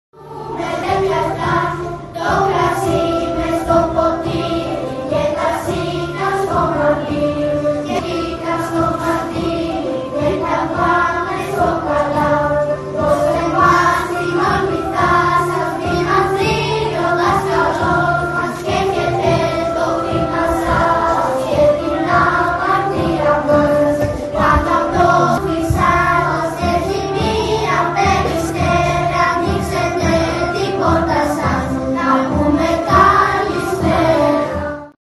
τα ανοιξιάτικα κάλαντα